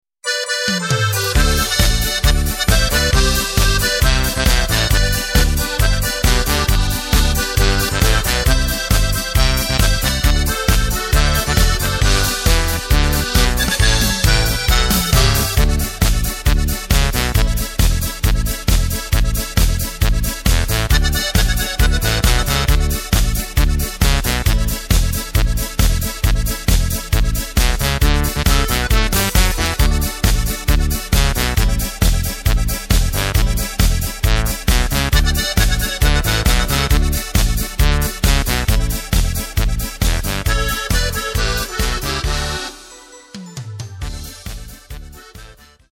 Takt:          2/4
Tempo:         135.00
Tonart:            G
Schöne Polka zum Abendbeginn aus dem Jahr 2011!
Playback mp3 mit Lyrics